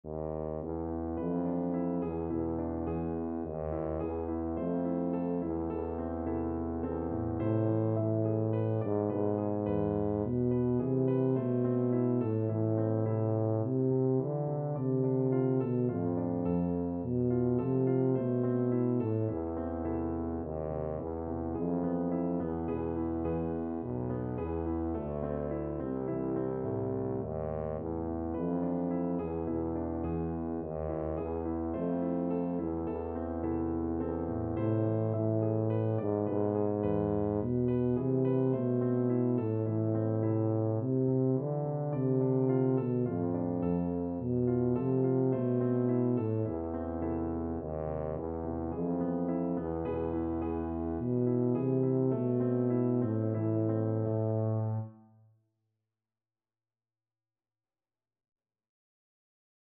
Tuba
A minor (Sounding Pitch) (View more A minor Music for Tuba )
3/4 (View more 3/4 Music)
Slow Waltz = c. 106
Traditional (View more Traditional Tuba Music)